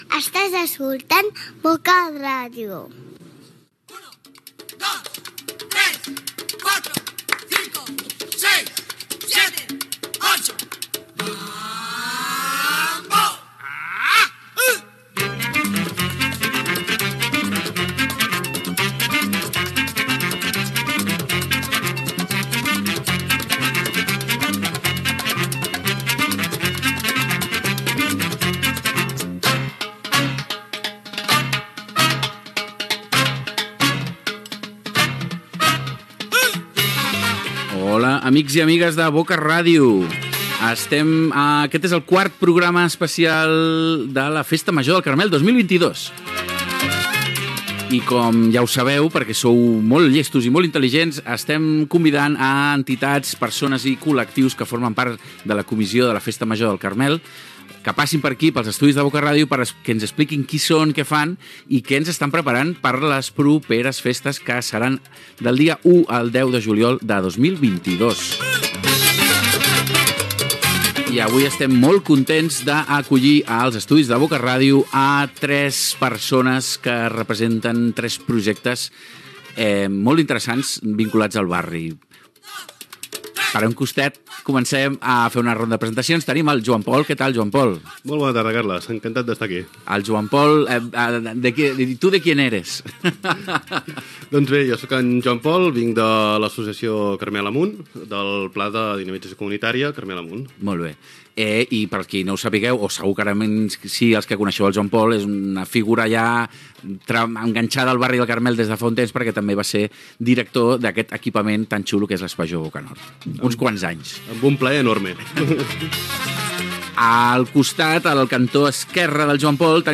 Indicatiu de l'emissora. Inici del quart episodi del programa per conèixer qui formarà part de la Comissió de la Festa Major del Carmel l'any 2022.
Informatiu